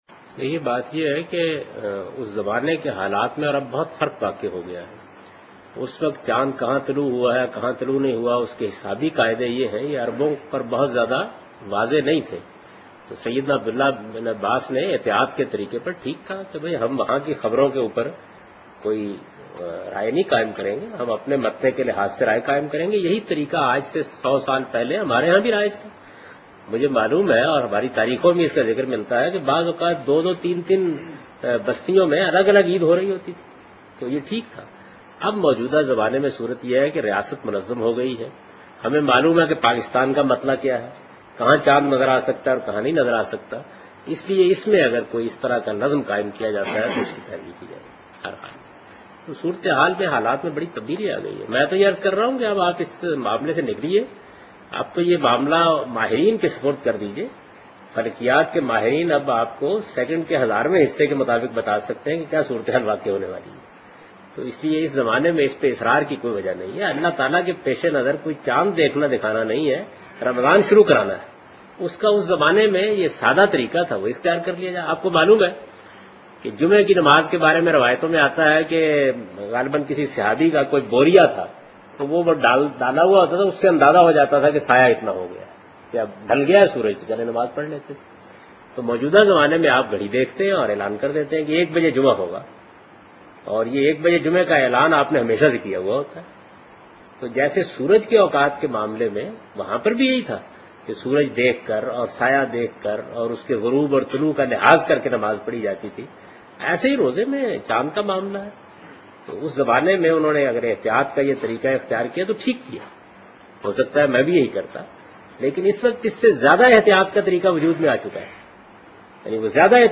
Category: Reflections / Questions_Answers /
In this video Javed Ahmad Ghamidi answers a question regarding "Ramadan and Moon of Eid"